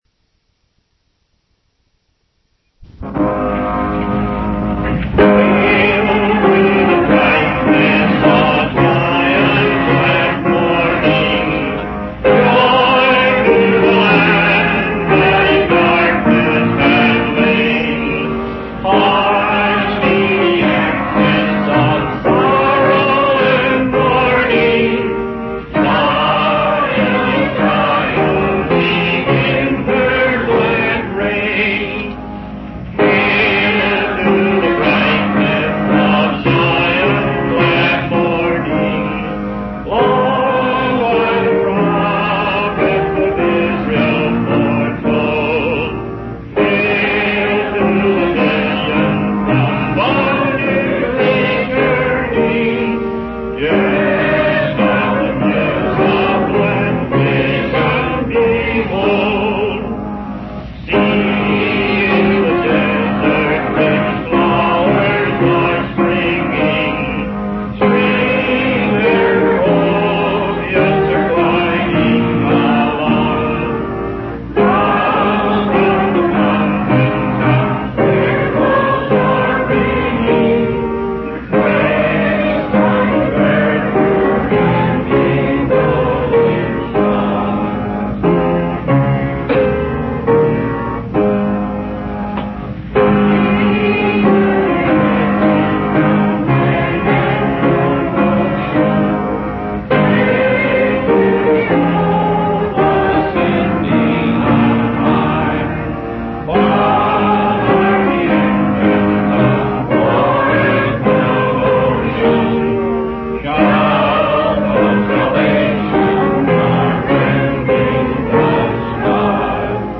From Type: "Discourse"
Given in Phoenix, 1973